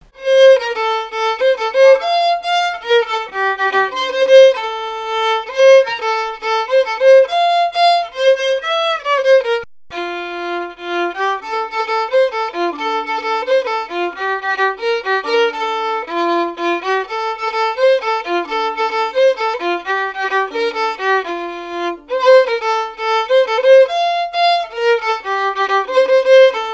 Chants brefs - A danser
danse : mazurka
Pièce musicale inédite